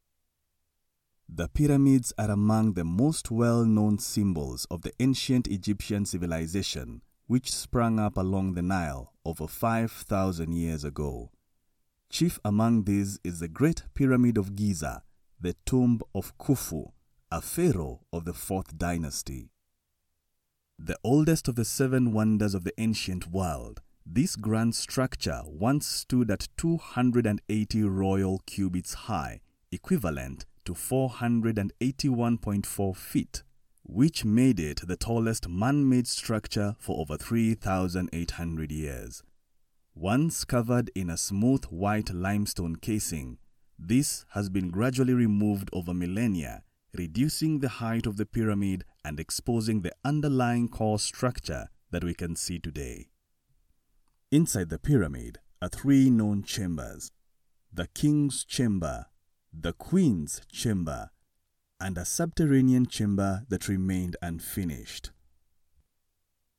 Documentaries
Scarlett 2i2 studio
DeepLow
MatureWarmFunnyAuthoritativeEmotional